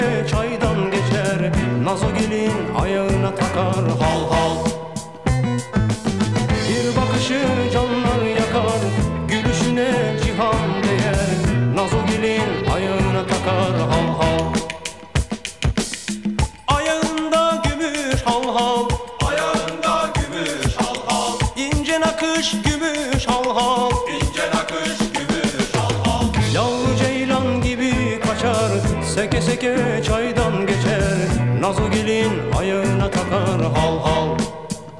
Жанр: Турецкая поп-музыка / Поп